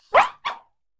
Cri de Pâtachiot dans Pokémon HOME.